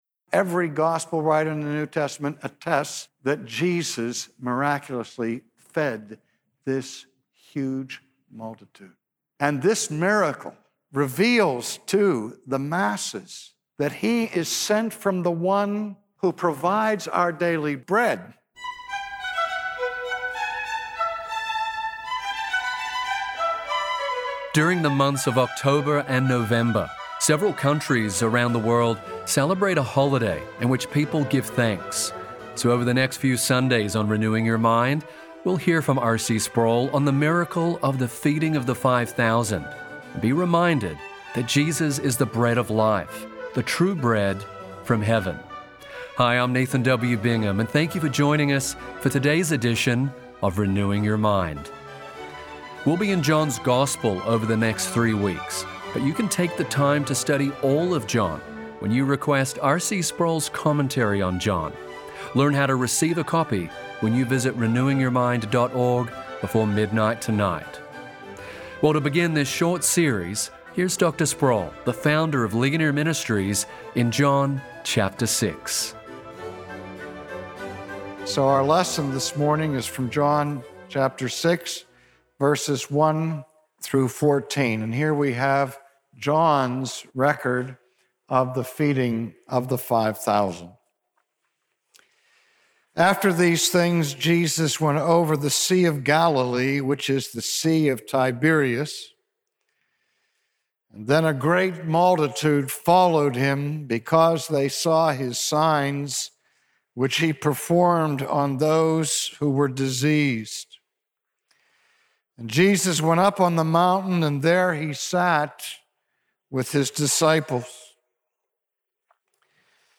The feeding of the five thousand was more than a compassionate miracle. Here, Jesus revealed His divine identity as the Bread of Life. Preaching from the gospel of John